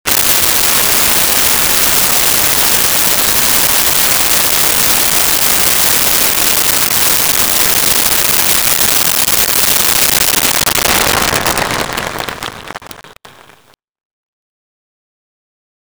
Concert Applause
Concert Applause.wav